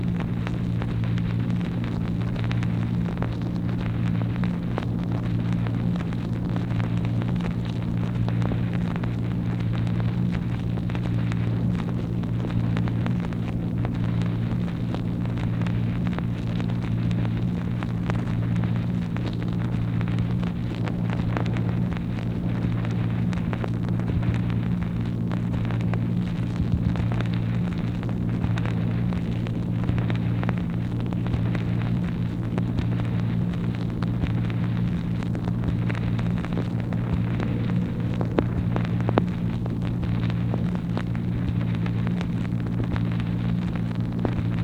MACHINE NOISE, April 8, 1964
Secret White House Tapes | Lyndon B. Johnson Presidency